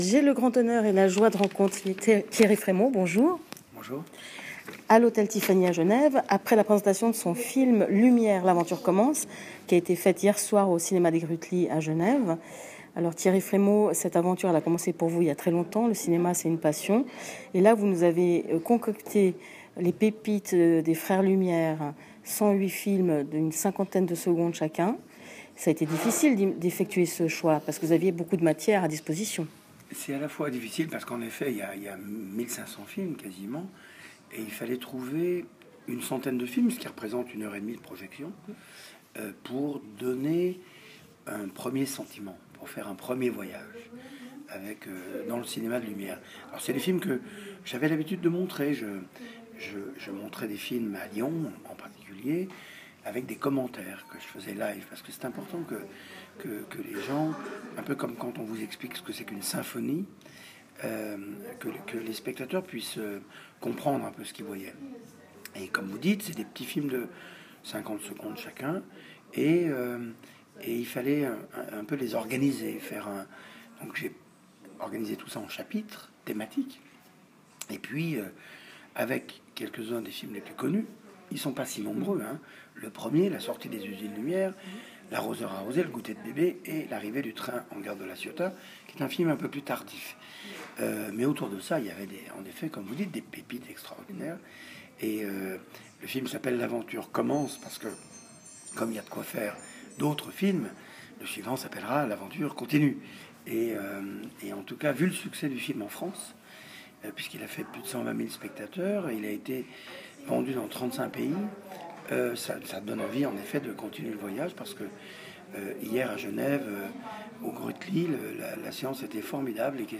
Lumière ! L’aventure commence – Entretien audio avec Thierry Frémaux